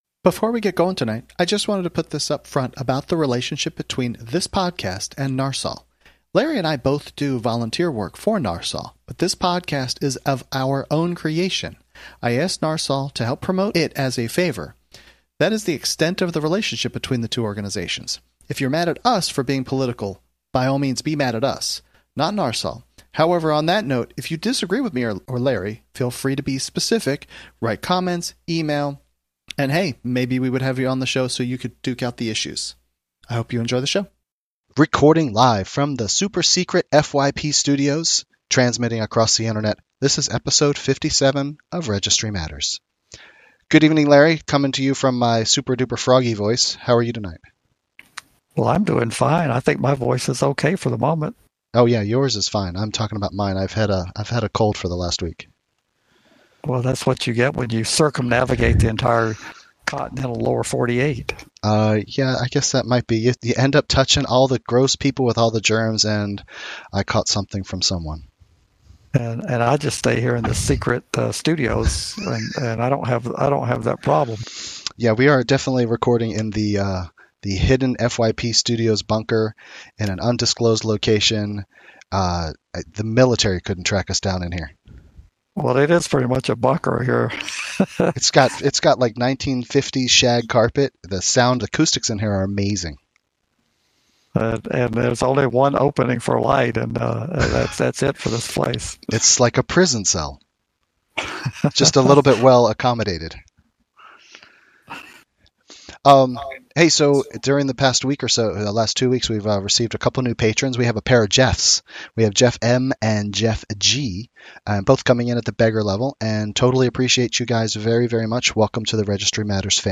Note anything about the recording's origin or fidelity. Recording from their super secret bunker.